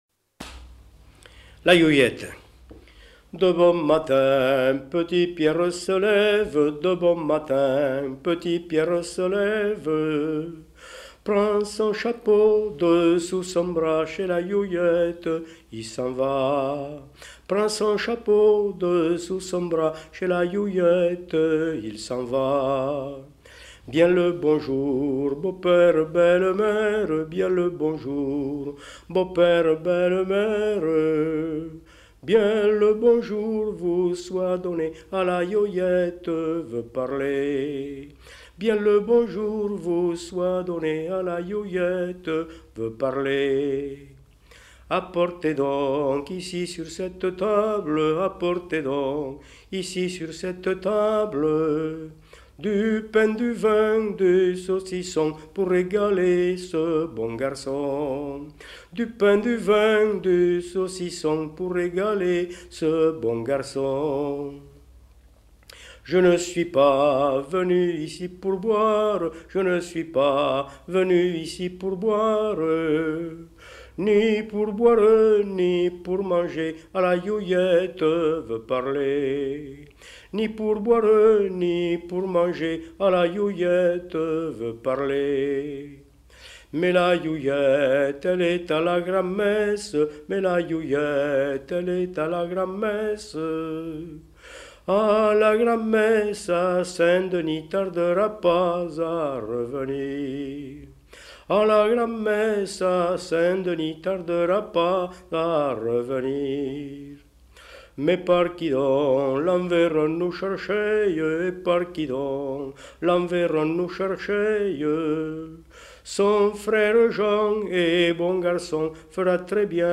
Aire culturelle : Périgord
Lieu : Daglan
Genre : chant
Effectif : 1
Type de voix : voix d'homme
Production du son : chanté